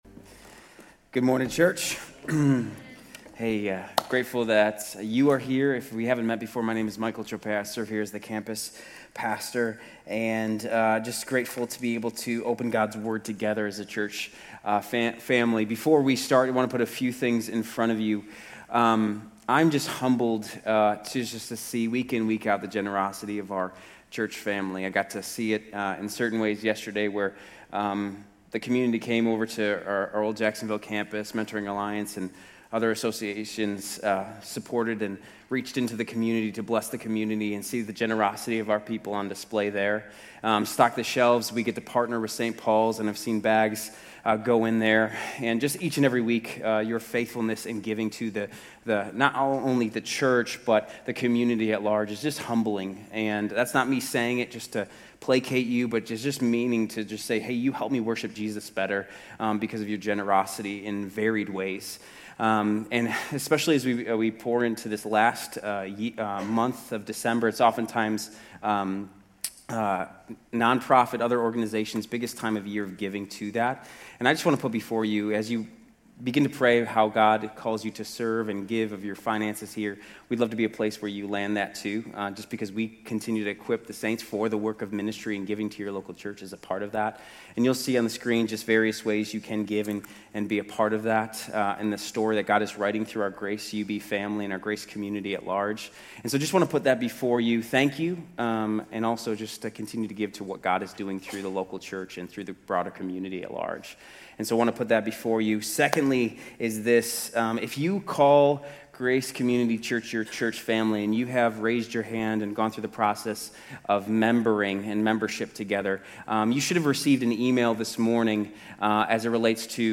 Grace Community Church University Blvd Campus Sermons 12_7 University Blvd Campus Dec 08 2025 | 00:35:29 1x 00:00 / 00:35:29 Subscribe Share RSS Feed Share Link Embed